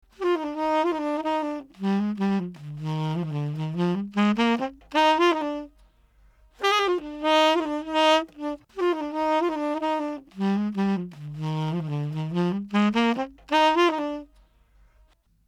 描述：爵士乐演奏者
Tag: 100 bpm Chill Out Loops Woodwind Loops 1.30 MB wav Key : Unknown